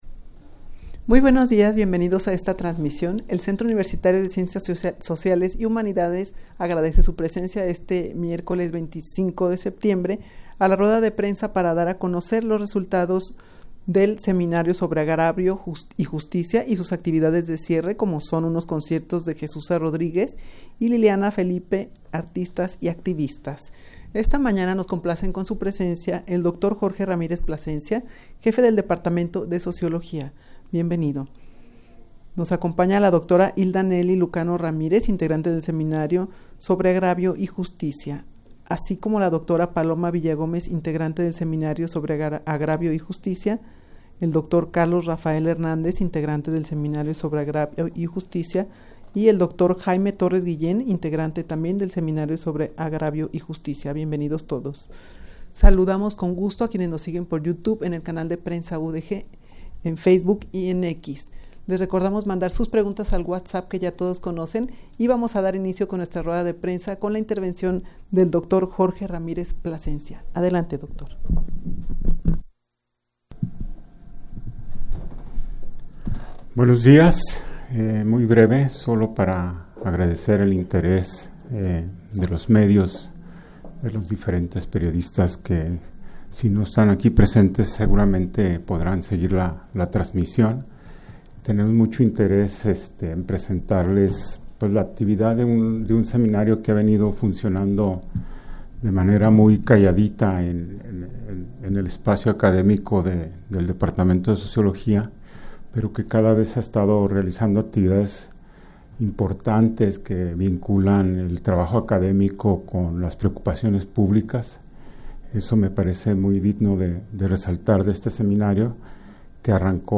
rueda-de-prensa-para-dar-a-conocer-los-resultados-del-seminario-sobre-agravio-y-justicia-.mp3